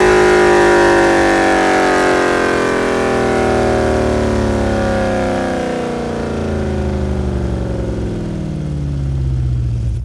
rr3-assets/files/.depot/audio/Vehicles/v8_03/v8_03_Decel.wav
v8_03_Decel.wav